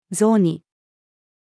雑煮-female.mp3